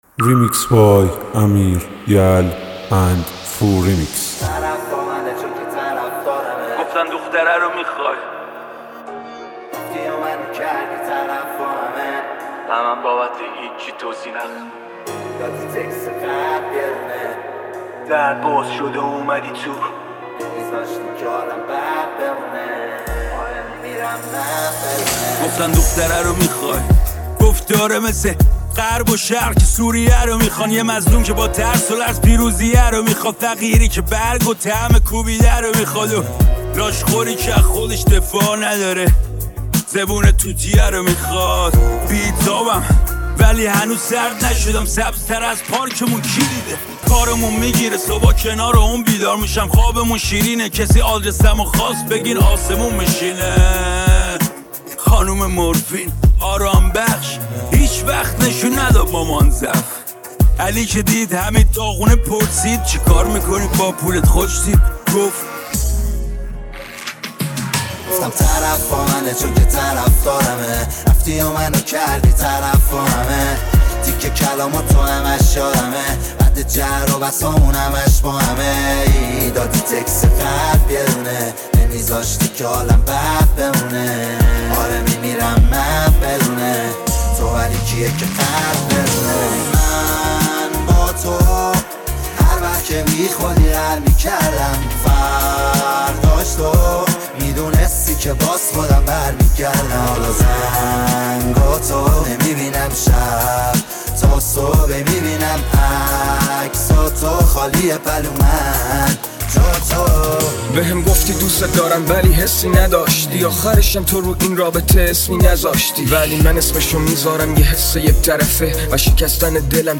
ریمیکس رپ ترکیبی